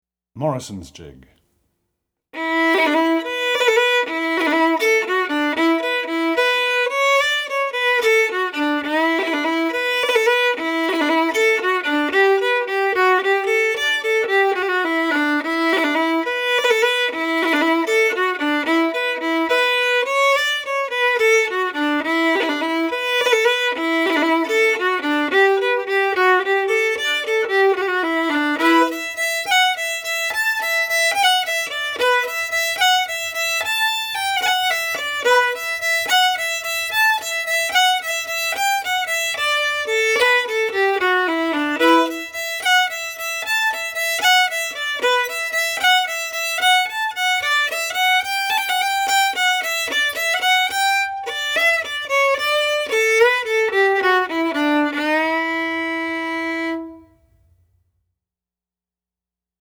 DIGITAL SHEET MUSIC - FIDDLE SOLO
Fiddle Solo, Celtic/Irish, Jig